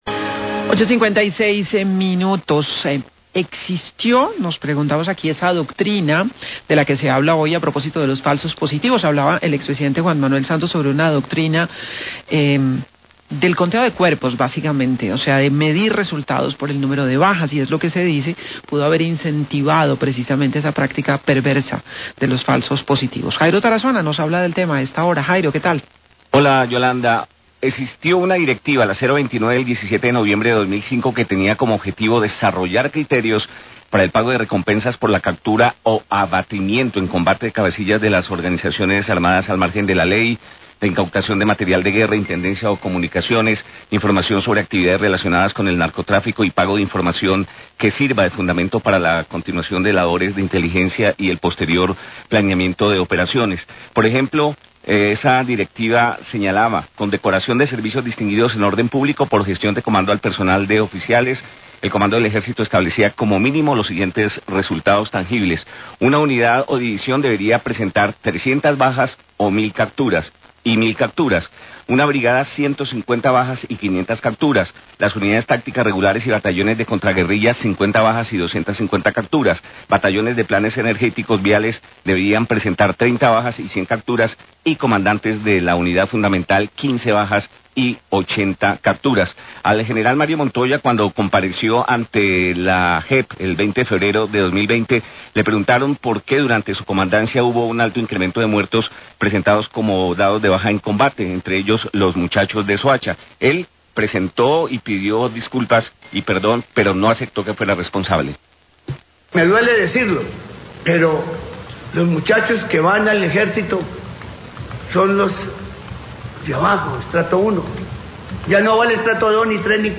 Radio
También se presentan audios de oficiales que hablaron sobre falsos positivos.